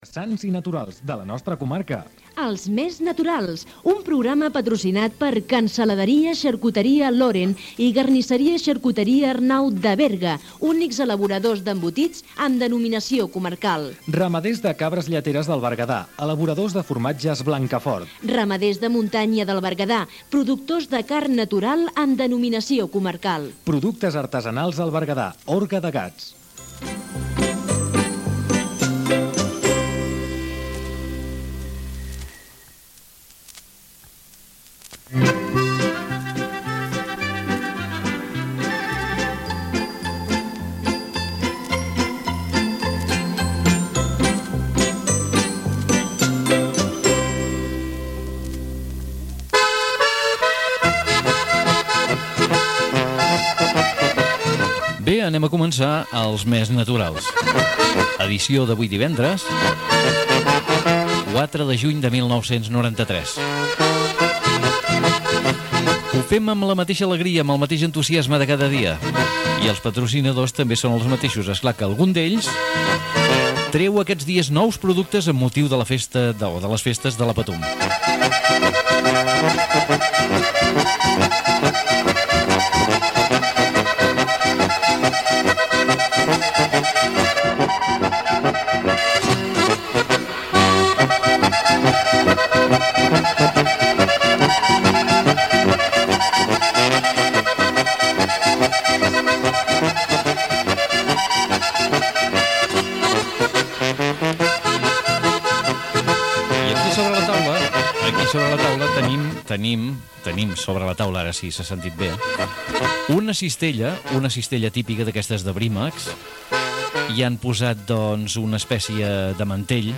Careta del programa, presentació
Gènere radiofònic Entreteniment